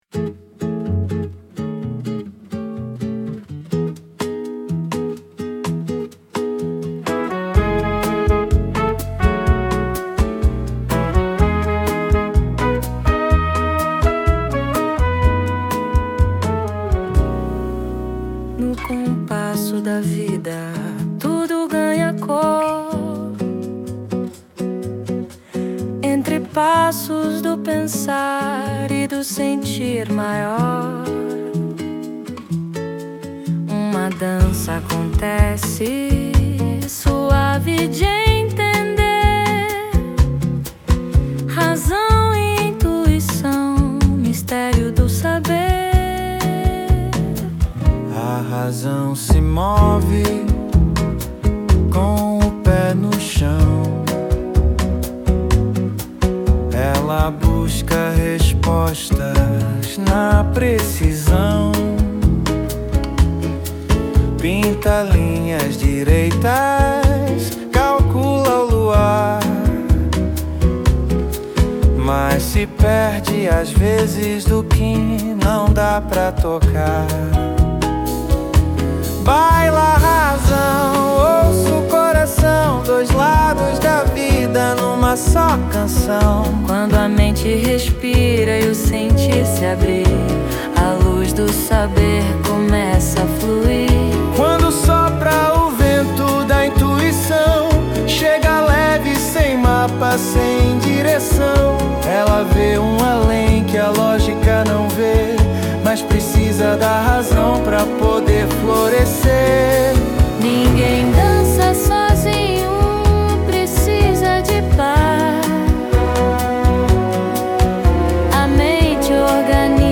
EstiloNew Age